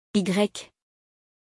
• Prononciation : [igʁɛk]
Lettre-Y.mp3